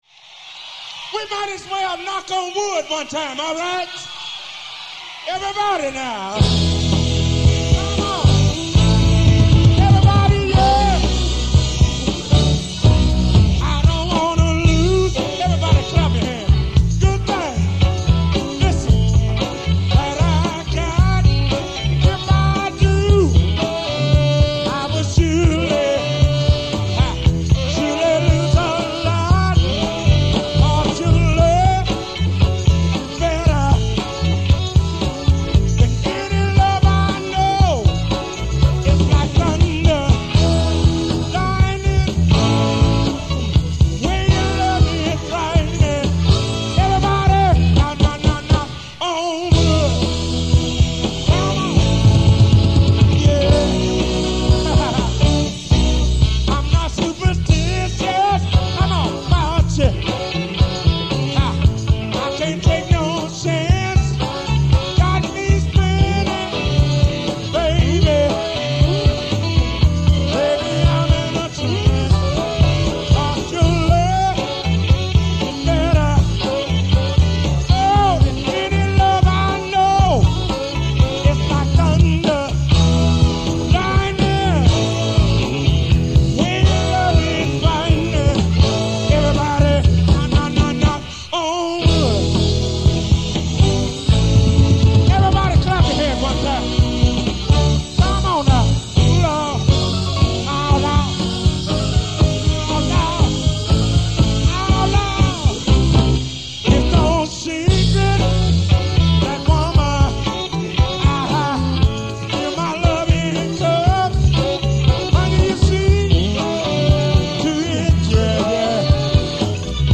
First performed: April 29, 1976 (Memphis, TN)